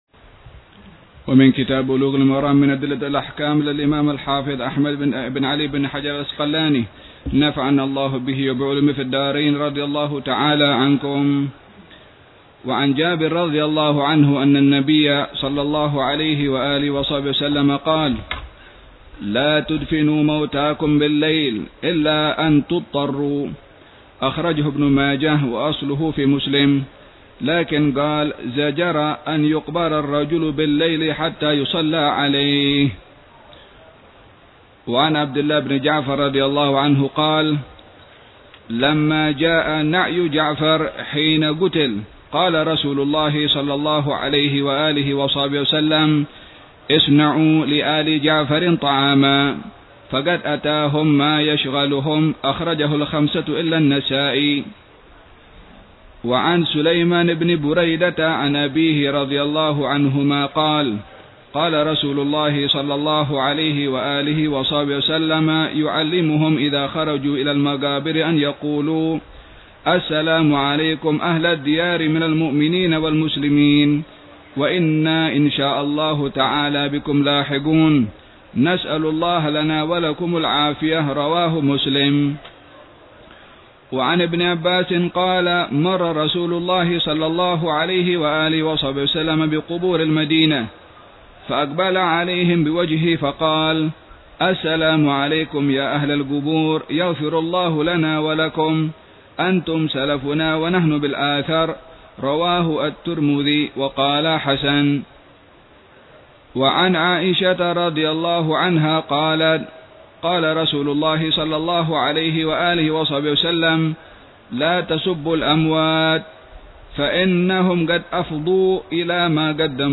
شرح الحبيب عمر بن حفيظ على كتاب بلوغ المرام من أدلة الأحكام للإمام الحافظ أحمد بن علي بن حجر العسقلاني، مختصر